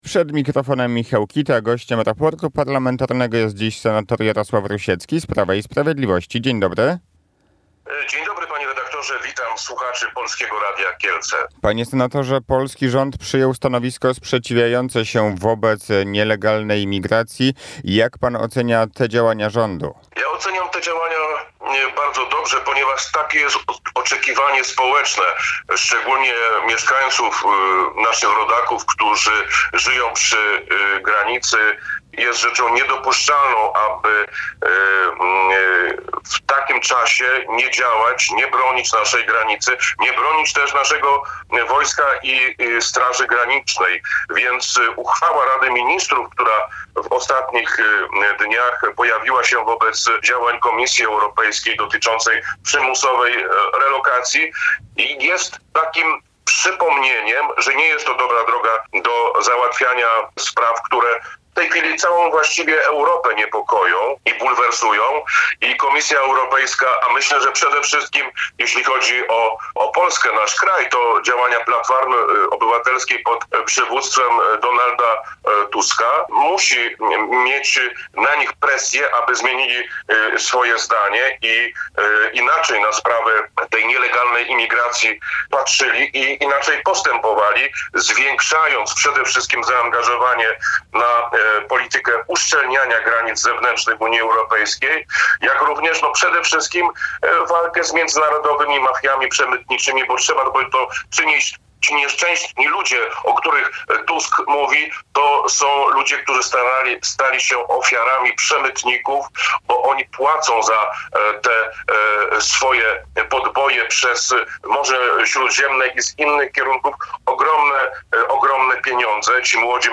– Naczelną zasadą rządu Prawa i Sprawiedliwości pod przywództwem premiera Mateusza Morawieckiego jest zapewnienie bezpieczeństwa naszych rodaków – stwierdził w Raporcie Parlamentarnym na antenie Radia Kielce senator Jarosław Rusiecki.